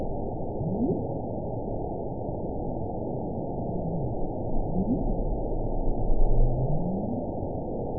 event 922021 date 12/25/24 time 10:21:26 GMT (11 months, 1 week ago) score 9.28 location TSS-AB10 detected by nrw target species NRW annotations +NRW Spectrogram: Frequency (kHz) vs. Time (s) audio not available .wav